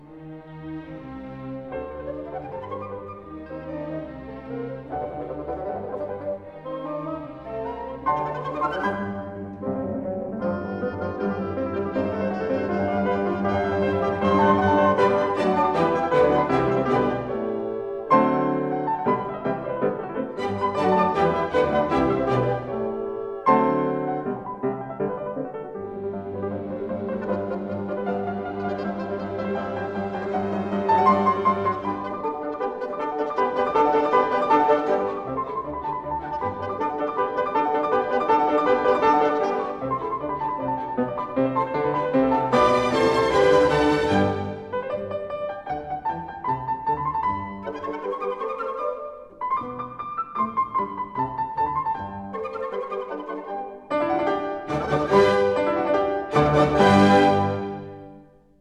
conductor
Stereo recording made on 20-23 July 1960
in No. 1 Studio, Abbey Road, London